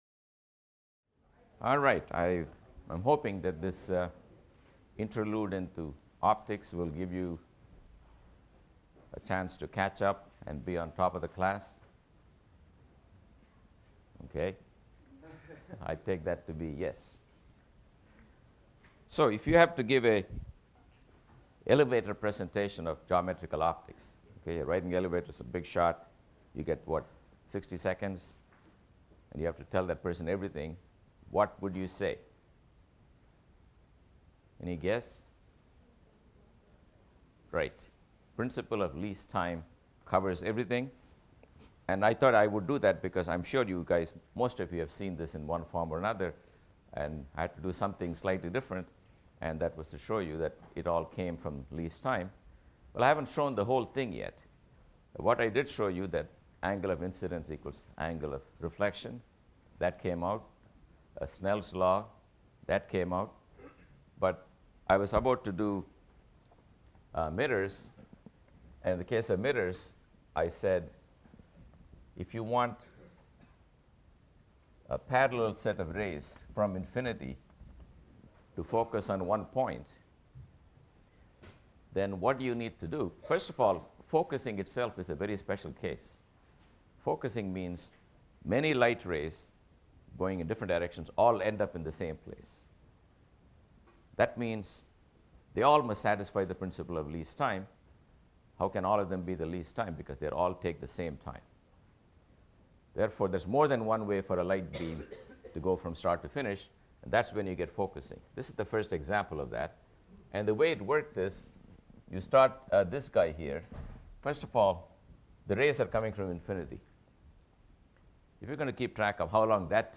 PHYS 201 - Lecture 17 - Ray or Geometrical Optics II | Open Yale Courses